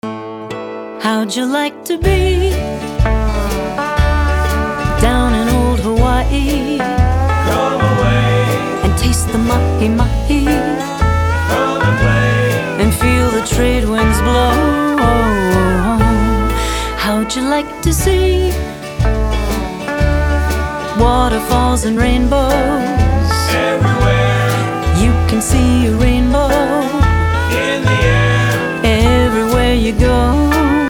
Vocal and